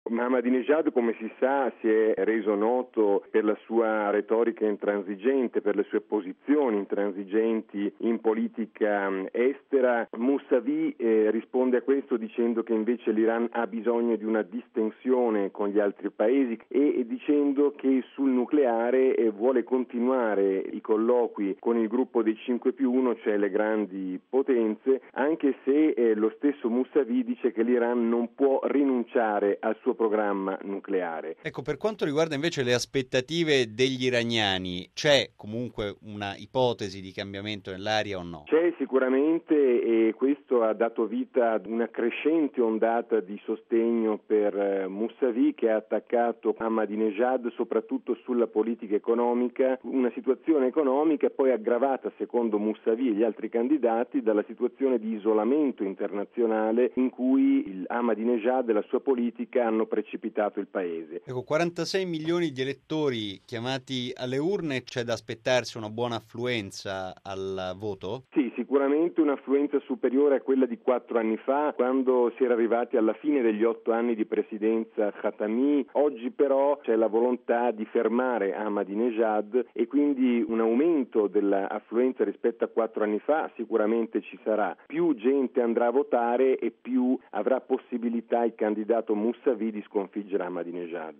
Nel caso dovesse mancare una vittoria netta, i primi due classificati andranno al ballottaggio il 19 giugno. Sulle differenze tra i favoriti la riflessione al microfono